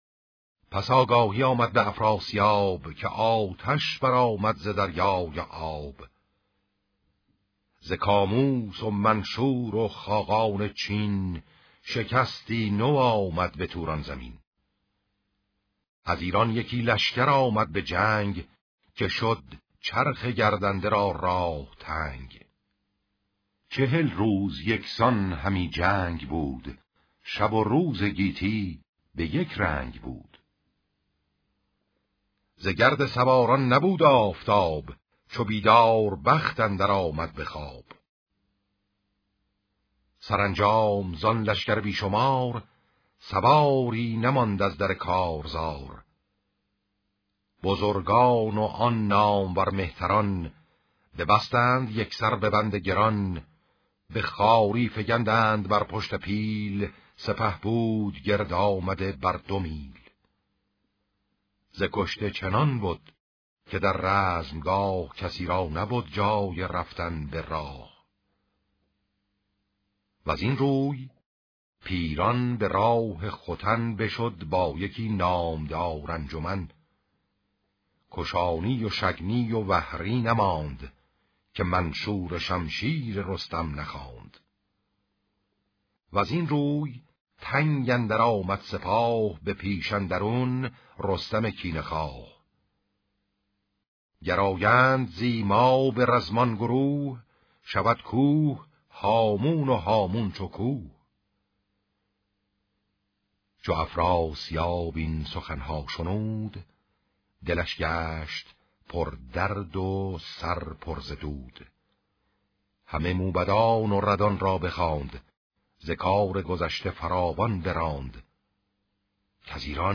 شاهنامه خوانی